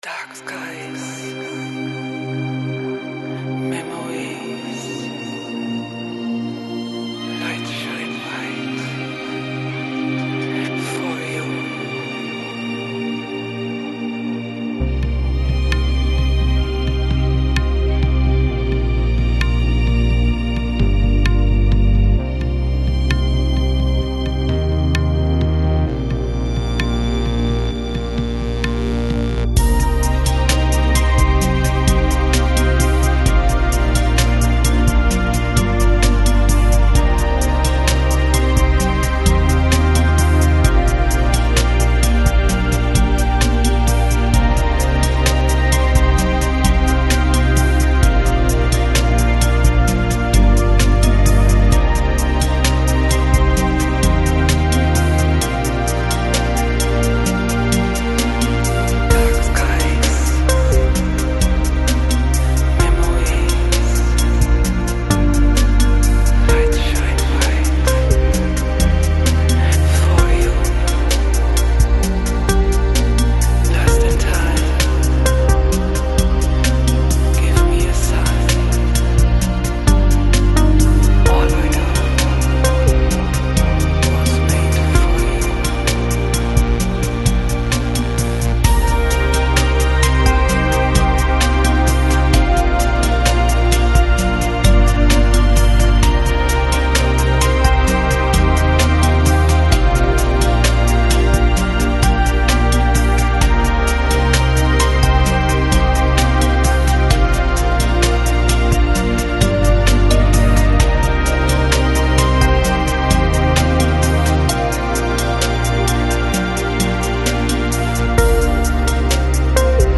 Chillout, Chillhouse, Downbeat, Lounge